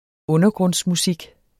Udtale [ ˈɔnʌgʁɔns- ]